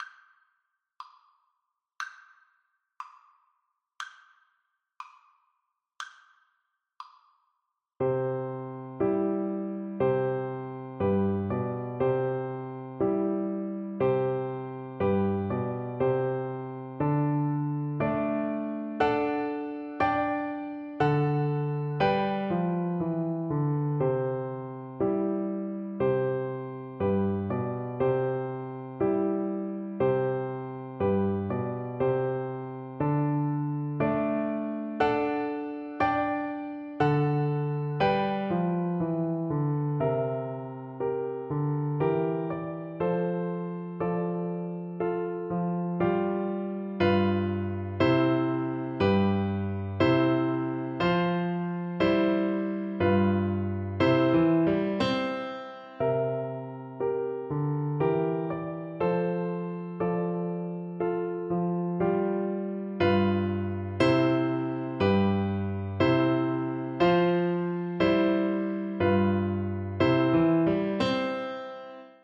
Flute
C major (Sounding Pitch) (View more C major Music for Flute )
Moderato
2/4 (View more 2/4 Music)
B5-C7
Traditional (View more Traditional Flute Music)